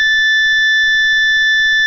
Zumbador Sonido Continuo y/o Intermitente
85dB
K71-ZUMBADOR CONTINUO
K71-ZUMBADOR CONTINUO.wav